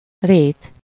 Ääntäminen
Synonyymit champ prairie Ääntäminen France: IPA: [pʁe] Haettu sana löytyi näillä lähdekielillä: ranska Käännös Ääninäyte 1. rét 2. kaszáló Suku: m .